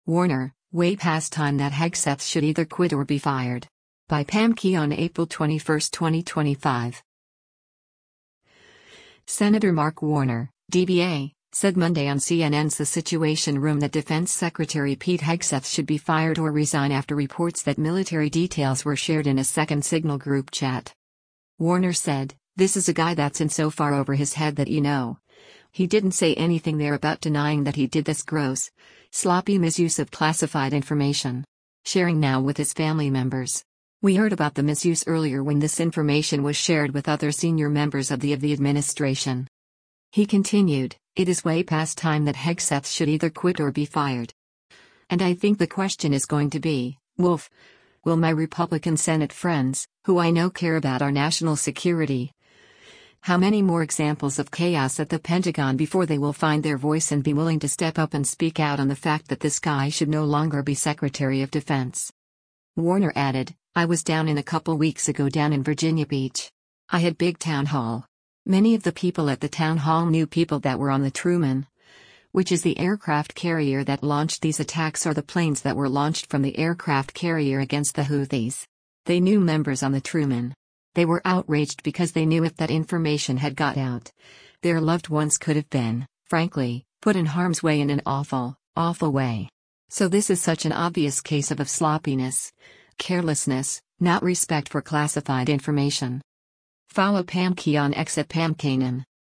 Senator Mark Warner (D-VA) said Monday on CNN’s “The Situation Room” that Defense Secretary Pete Hegseth should be fired or resign after reports that military details were shared in a second Signal group chat.